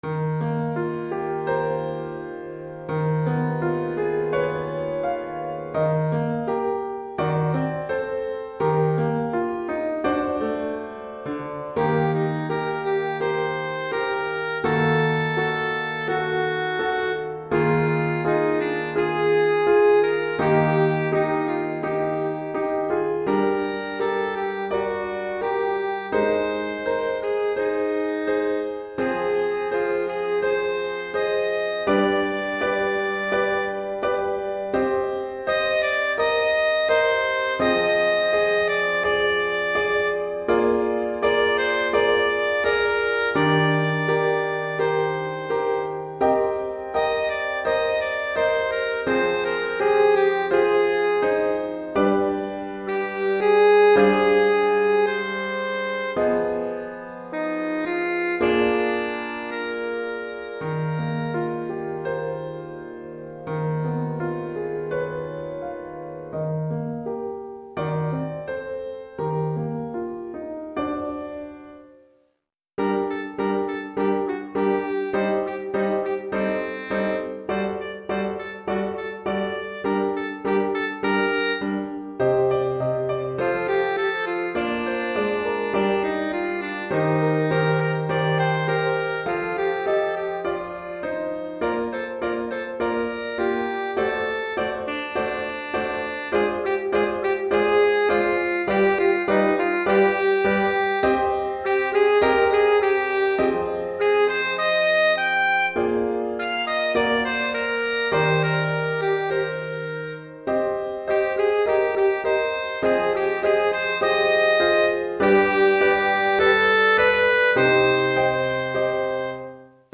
Pour saxophone alto et piano